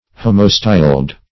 Homostyled \Ho"mo*styled\, a. [Homo- + style.] (Bot.)